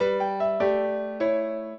piano
minuet14-3.wav